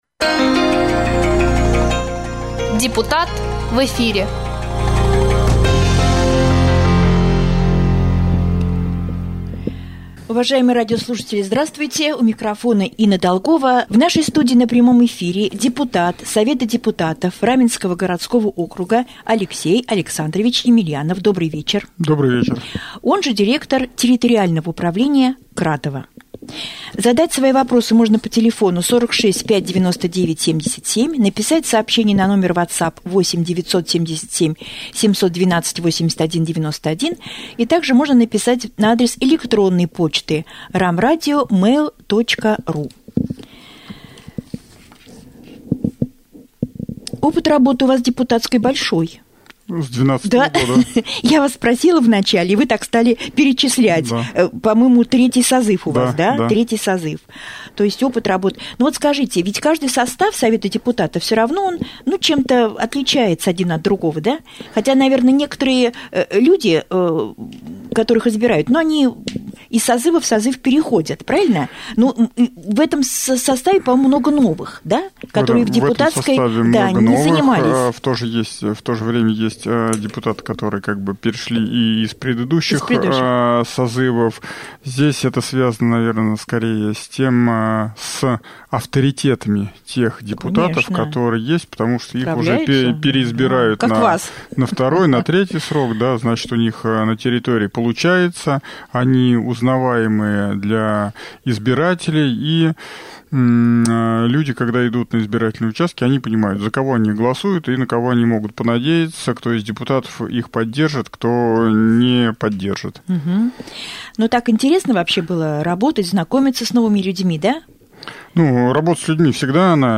?Гость студии Емельянов Алексей Александрович Депутат Совета депутатов Раменского городского округа рассказал: